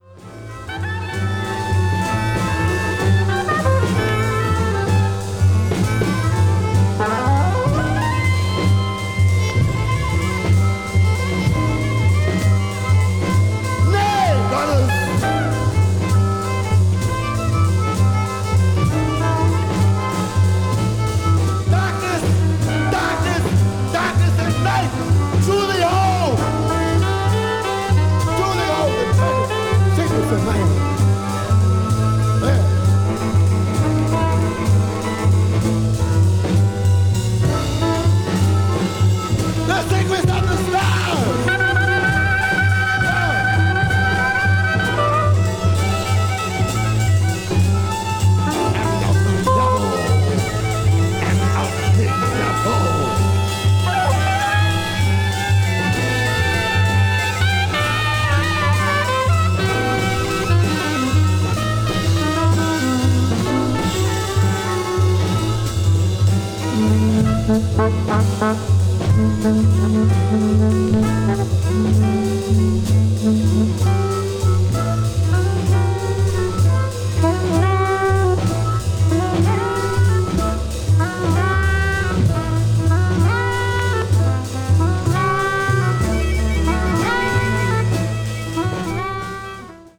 ワルツ・ビートを基調にブルージーなグルーヴを披露した名演